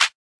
Stunt On You Clap.wav